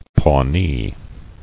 [Paw·nee]